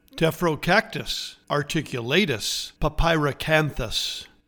Pronounciation:
Te-fro-CAC-tus ar-tic-u-LAY-tus pa-pie-ra-CAN-thus